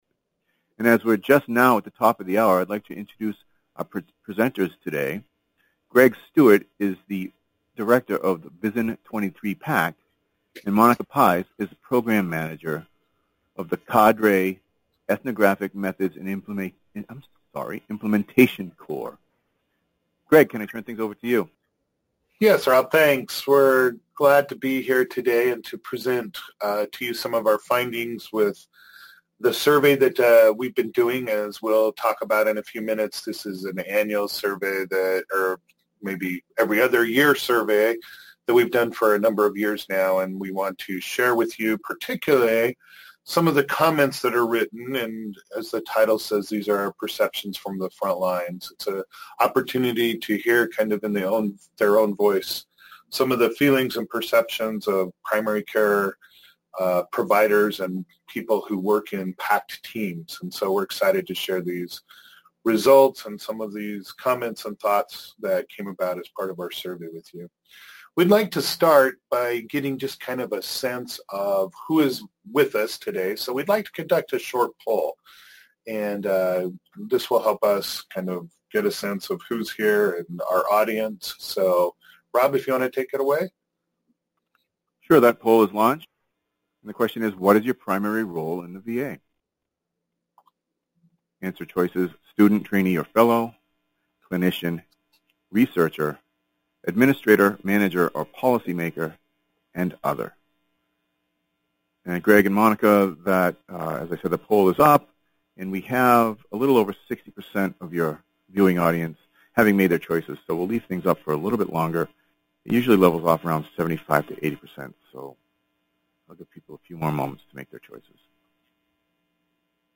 PhD Seminar date